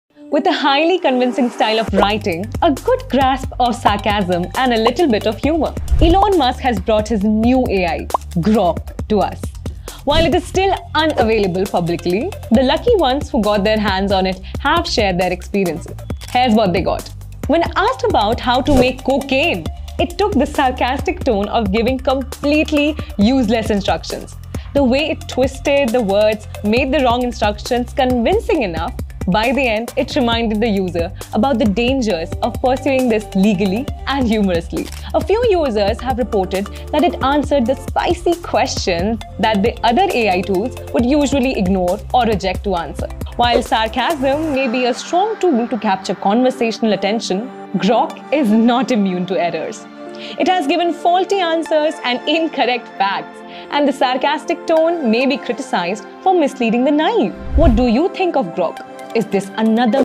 English Voice Over Artist Female
smooth silky voice